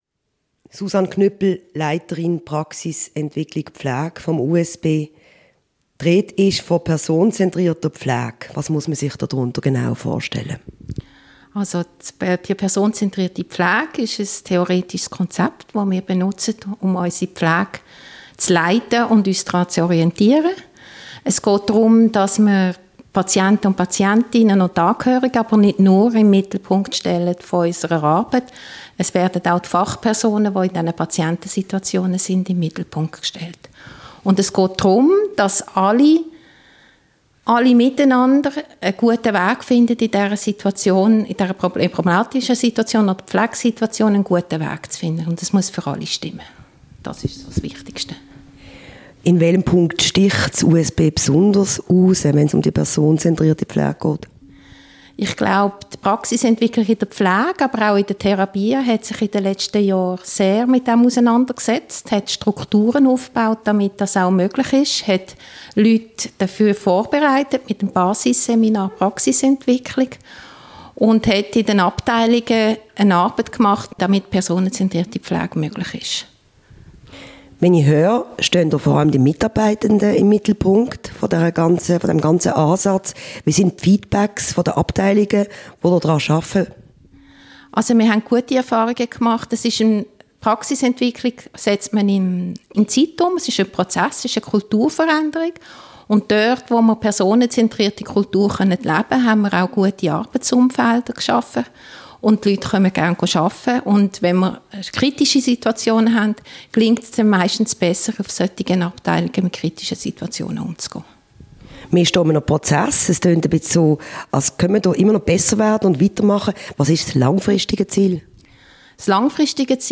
Interview mit der Leiterin Praxisentwicklung Pflege